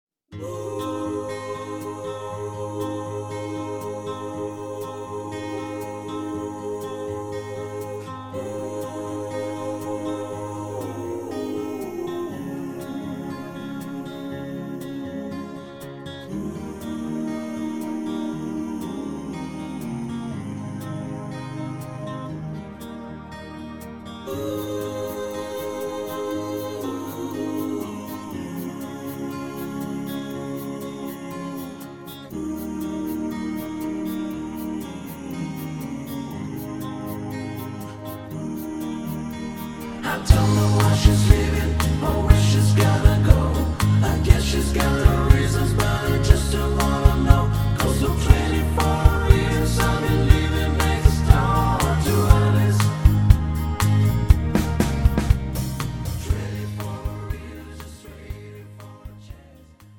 음정 -1키
장르 pop 구분